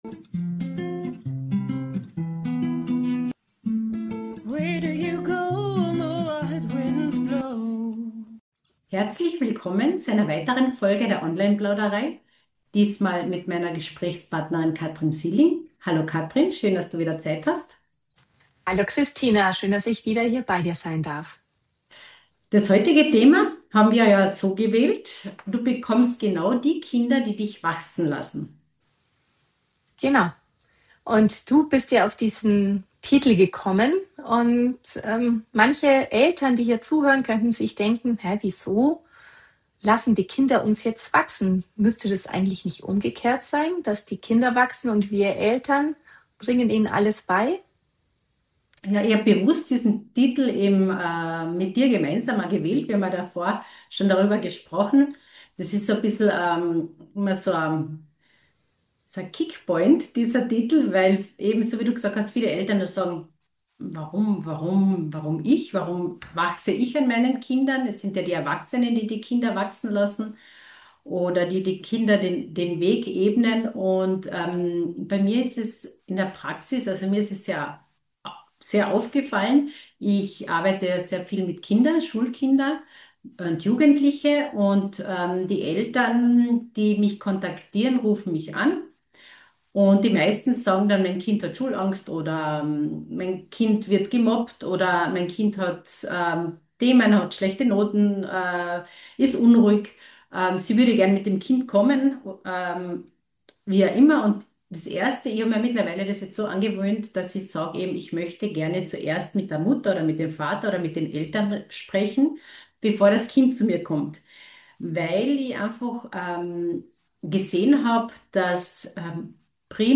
Online Plauderei - ehrliche Gespräche über das Begleiten von Kindern und Jugendlichen, voller Herz, Erfahrung und neuer Perspektiven.